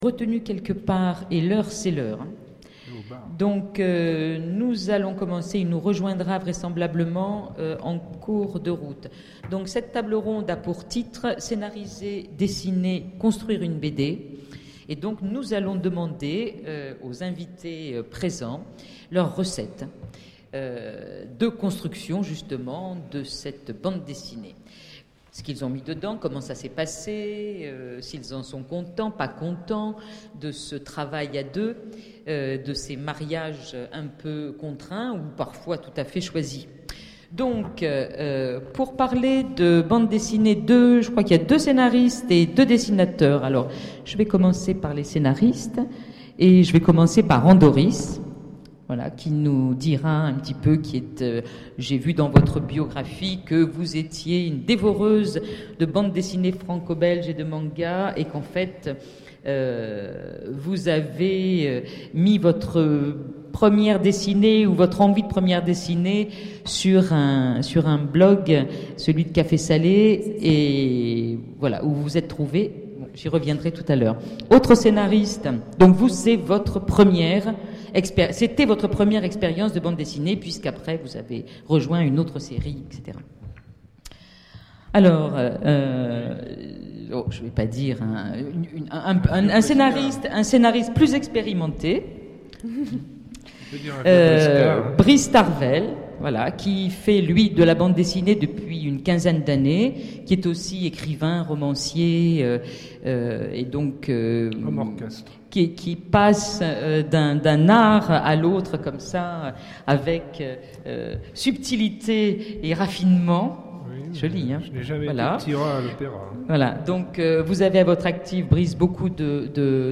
Imaginales 2013 : Conférence Scénariser, dessiner...